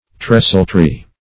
Search Result for " trestletree" : The Collaborative International Dictionary of English v.0.48: Trestletree \Tres"tle*tree`\, n. (Naut.)